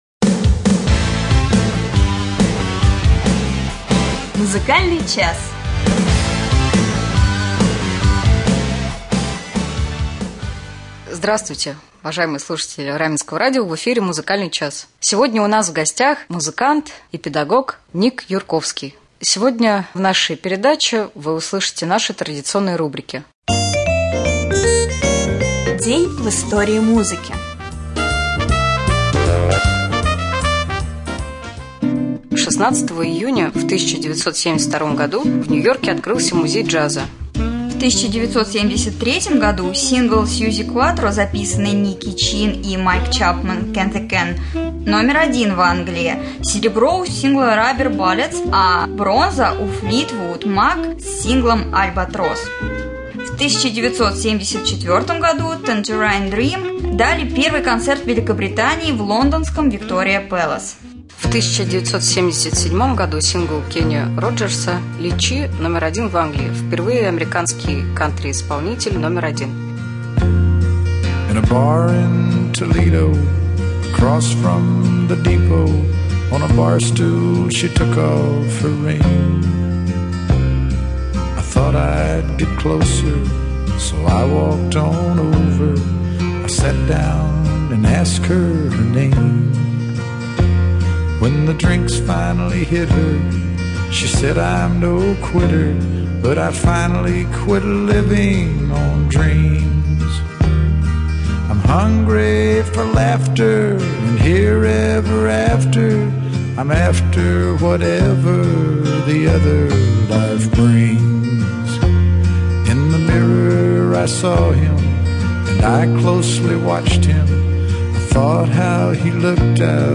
Гостем студии